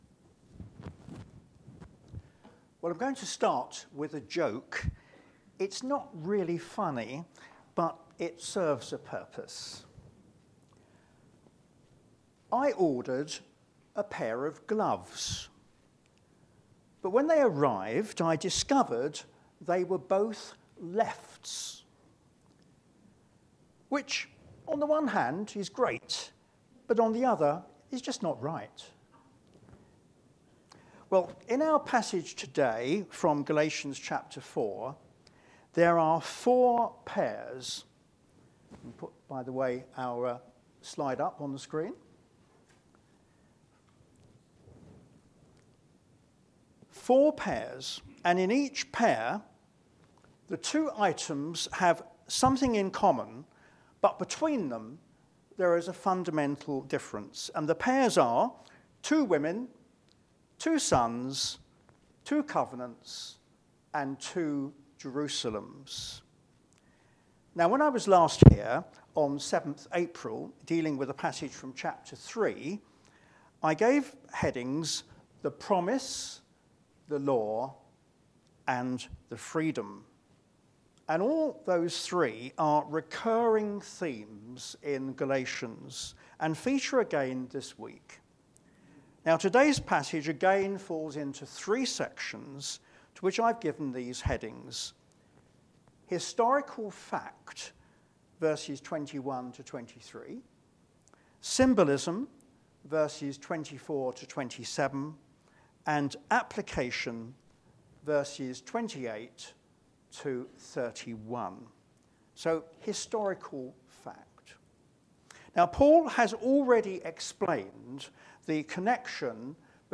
Media Library Media for a.m. Service on Sun 05th May 2024 10:30 Speaker
Theme: Sermon